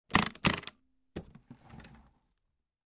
Звуки чемодана